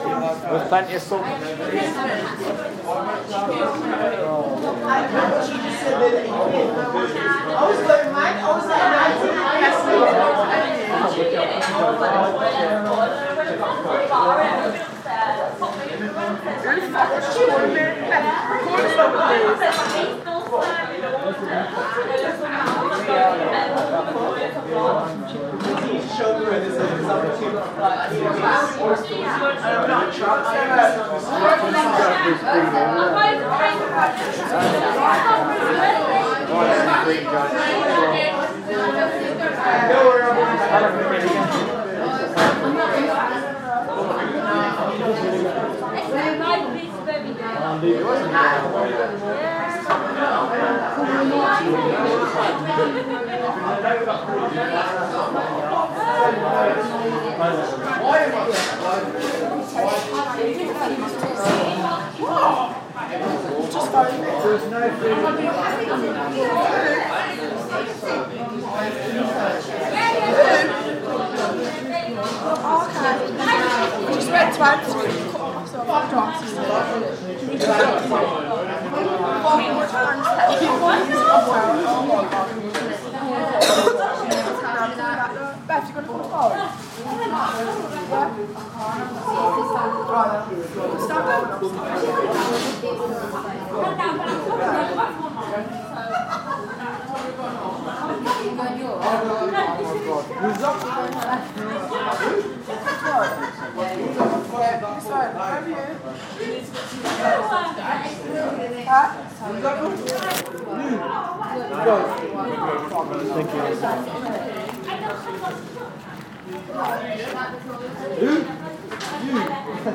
Ambience: Takeaway chatter